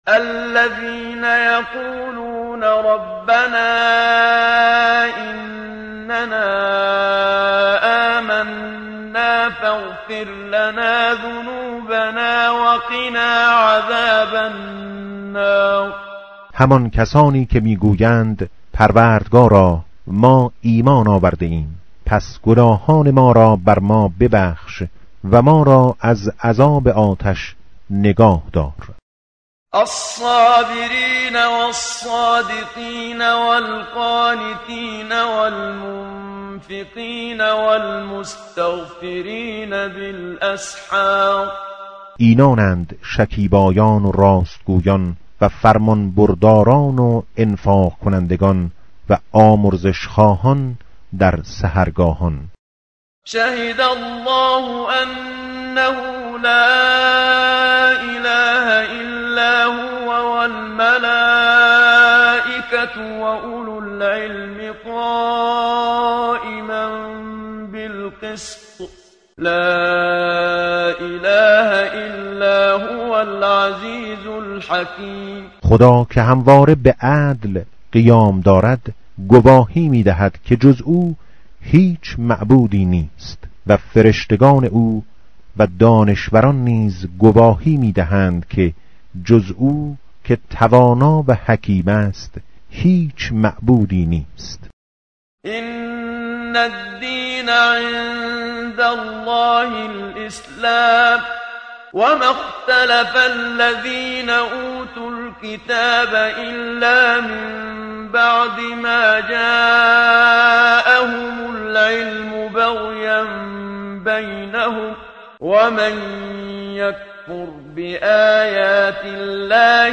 tartil_menshavi va tarjome_Page_052.mp3